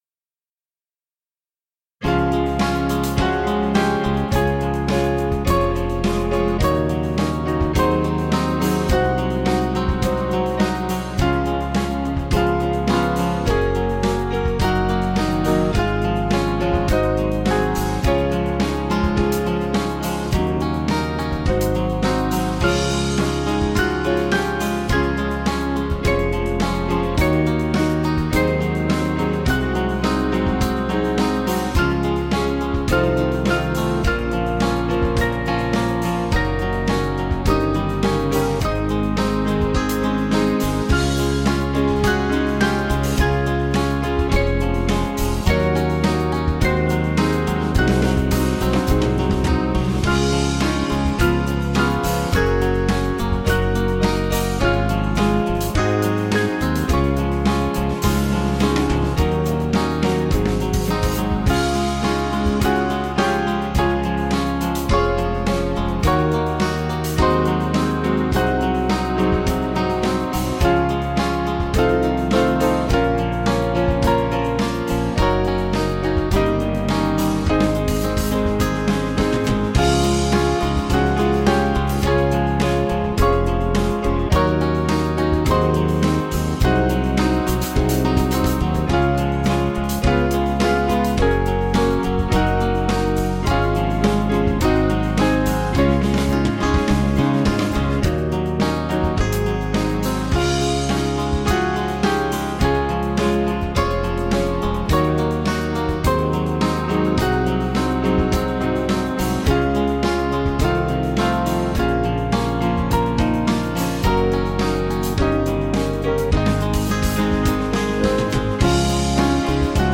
Small Band
(slow)   489.5kb